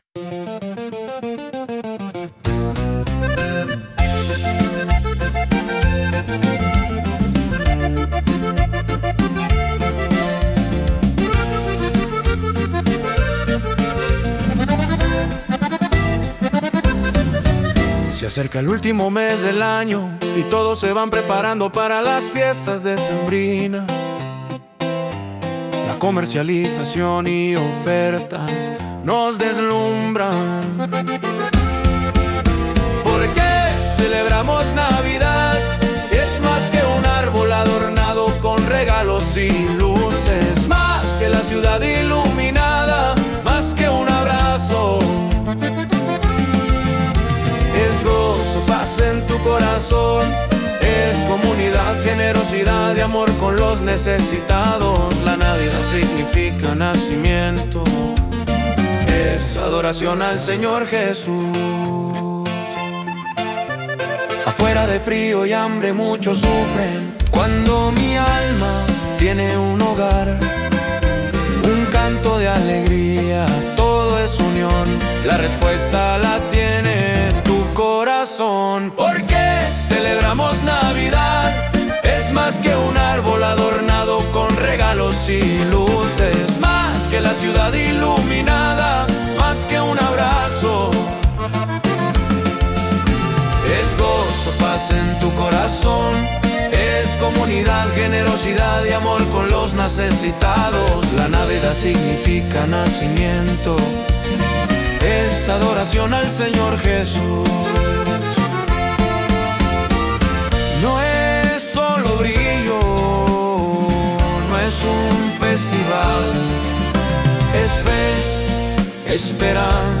Corrido